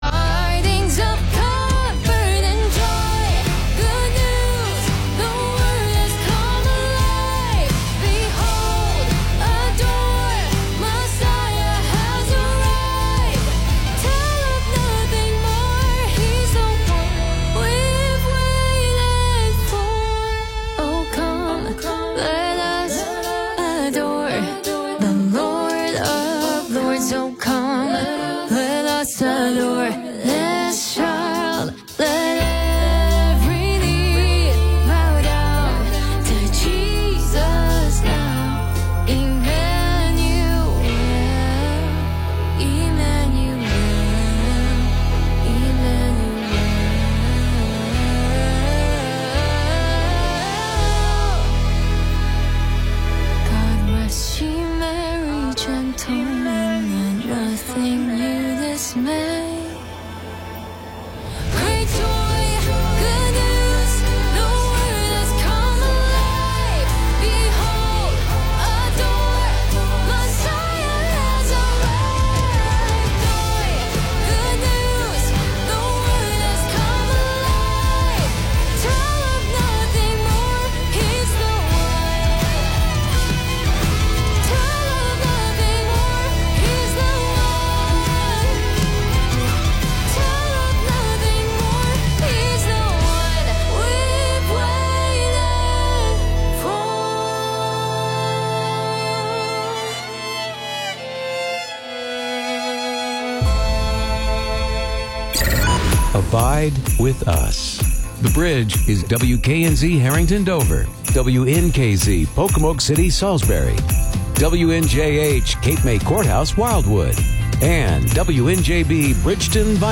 Upbeat, encouraging, humorous, and fun conversations with good friends!